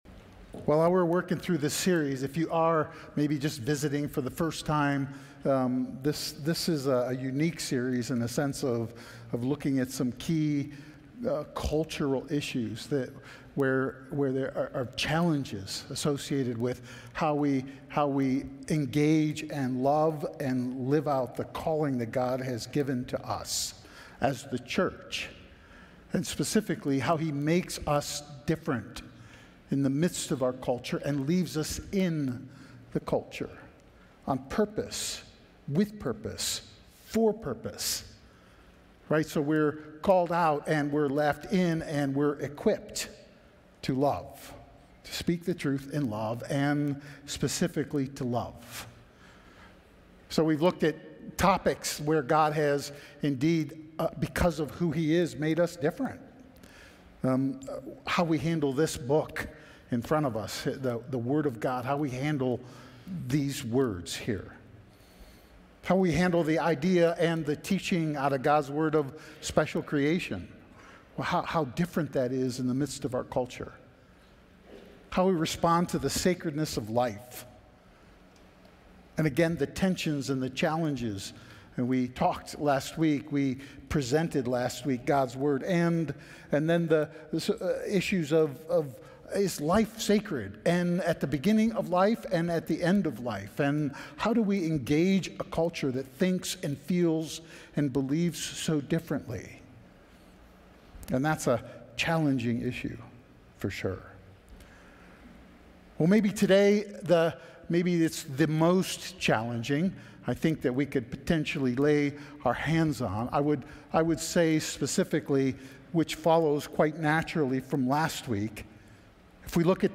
We are Audio File Sermon Notes More From This Series All We Need 2025-02-16 His Covenant 2025-02-09 His Redemption 2025-02-02 His Image 2025-01-19 His Creation 2025-01-12 His Words 2025-01-05